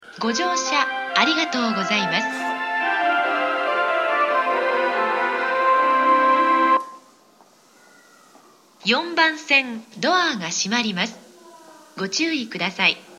発車メロディー余韻切りです。